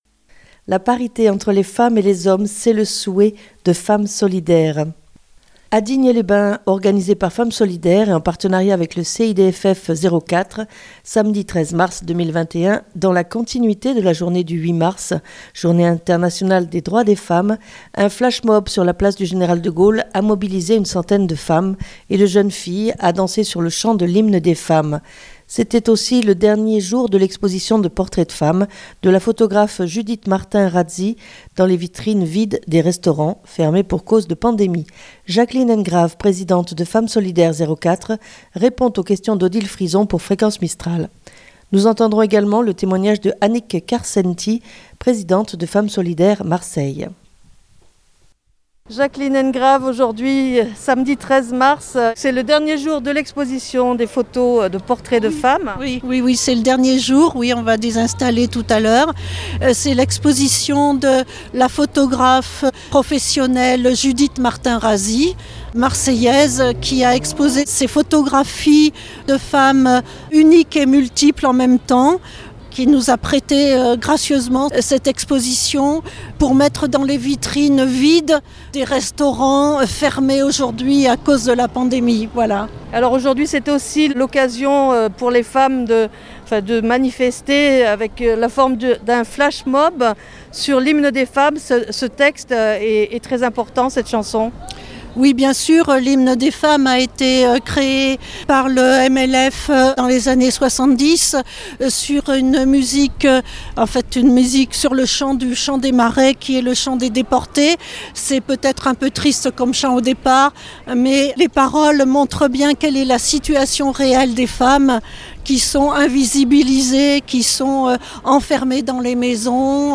A Digne les bains, organisé par Femmes Solidaires, en partenariat avec le CIDFF 04, Samedi 13 mars 2021, dans la continuité de la journée du 8 mars, journée internationale des Droits des Femmes, un flashmob sur la place du Général de Gaulle, a mobilisé une centaine de femmes, et de jeunes filles a danser sur le chant de l'Hymne des femmes.